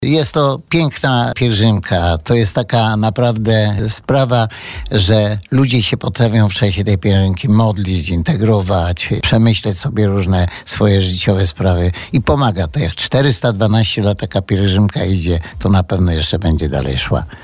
Temat pielgrzymki poruszyliśmy podczas dzisiejszej rozmowy z burmistrzem Żywca Antonim Szlagorem.